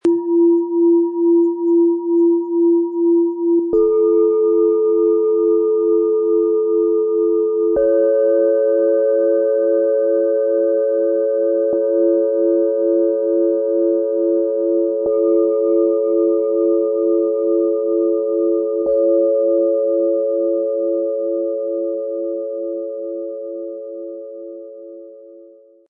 Wiegende Stille - Erdend, zentrierend, erhebend - Set aus 3 Klangschalen, Ø 11,4 - 14,9 cm, 1,41 kg
Diese große Schale schenkt stabile Ruhe und steht mit ihrem tiefen Ton kraftvoll im Raum.
Ihr warmer Klang fühlt sich freundlich und einladend an.
Ihr klarer, heller Ton wirkt freudvoll und öffnend.
Im Sound-Player - Jetzt reinhören können Sie den Original-Klang genau dieser Schalen anhören – und die besondere Schwingung des Sets erleben.
Ein passender Klöppel ist inklusive – damit schwingen die Schalen warm und harmonisch.